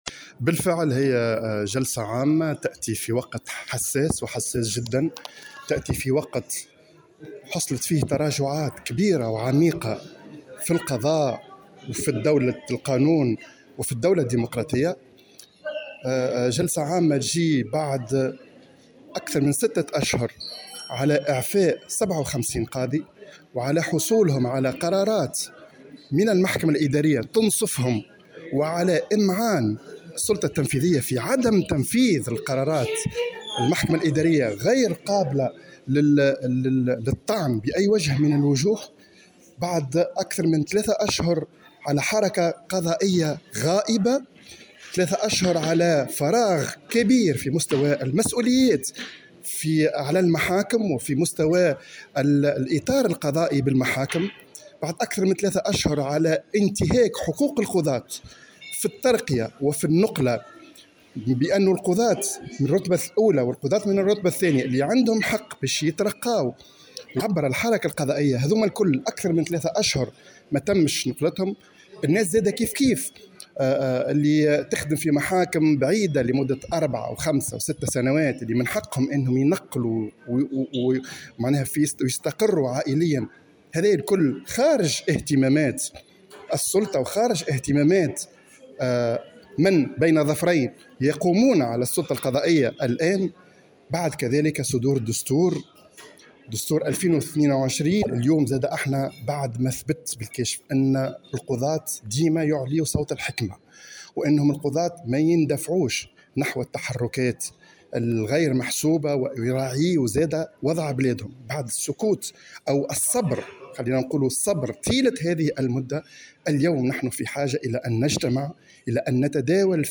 على هامش جلسة عامة للجمعية، تنعقد بالحمامات